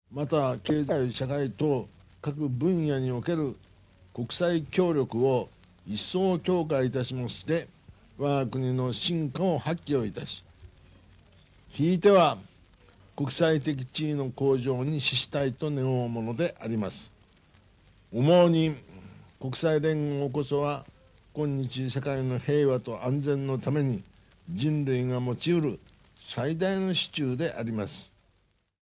当時の国務大臣である鳩山一郎氏による加盟実現についての演説。